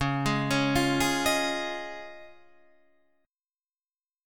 C# Major Add 9th